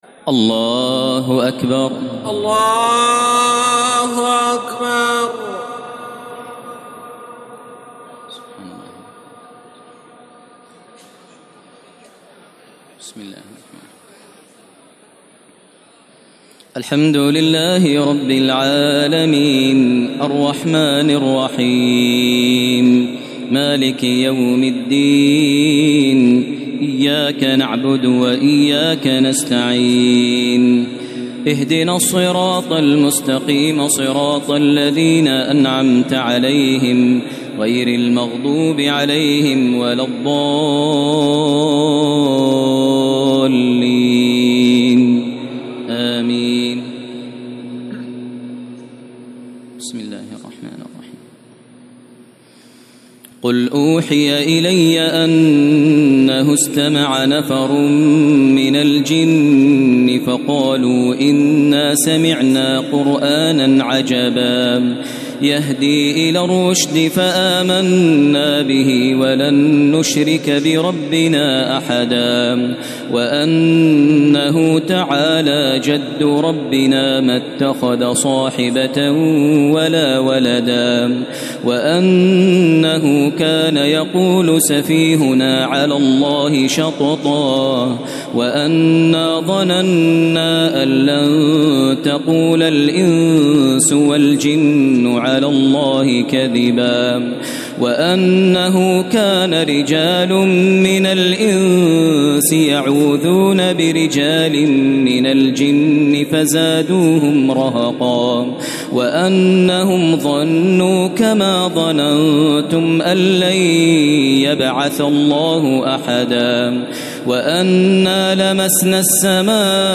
تراويح ليلة 28 رمضان 1432هـ من سورة الجن الى المرسلات Taraweeh 28 st night Ramadan 1432H from Surah Al-Jinn to Al-Mursalaat > تراويح الحرم المكي عام 1432 🕋 > التراويح - تلاوات الحرمين